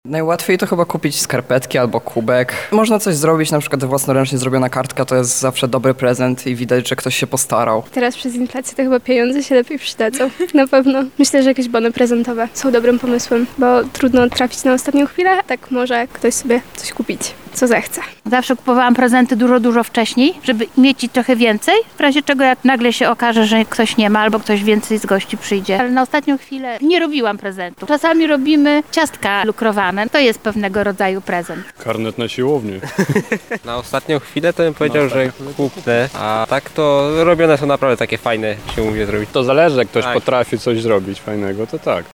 [SONDA]Co na nas czeka pod choinką?
O inspiracje świąteczne zapytaliśmy mieszkańców Lublina:
sonda